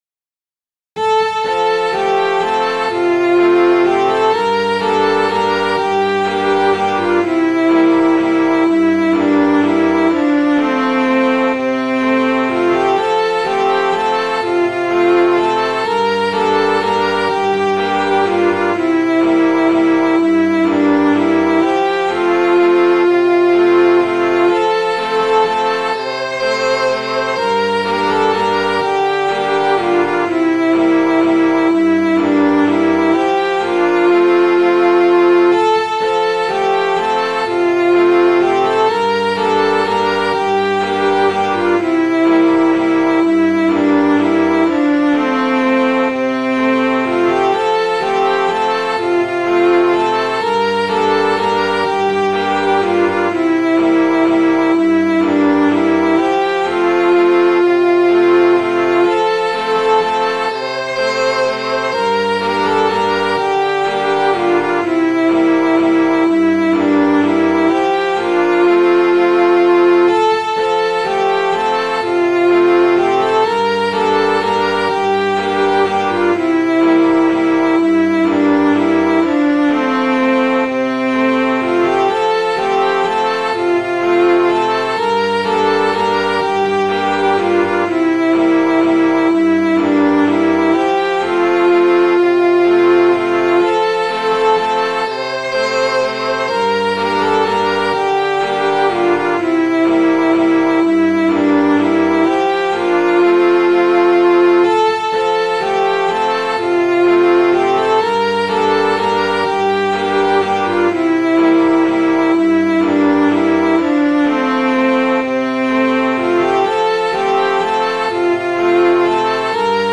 Midi File, Lyrics and Information to Sir Peter Parker